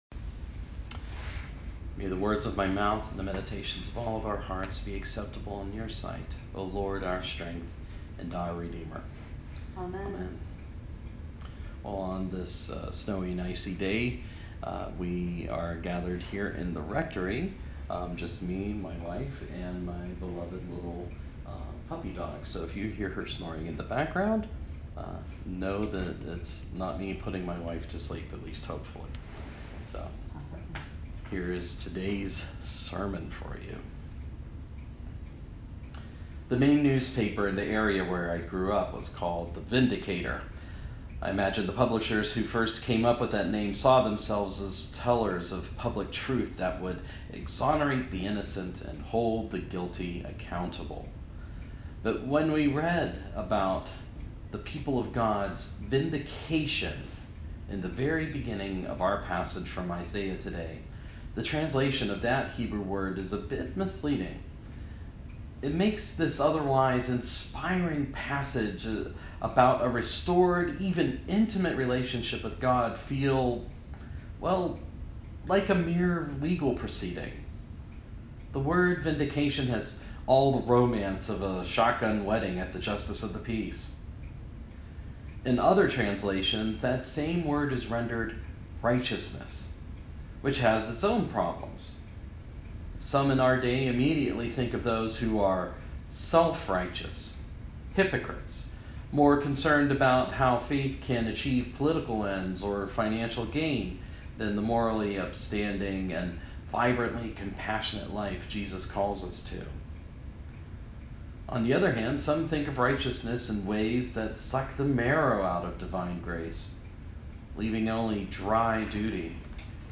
Sermon – Second Sunday after Epiphany
Though we were iced out of formal services, the proclamation of the Word goes forth via technology. In Isaiah today we are comforted with the hope of divine restoration.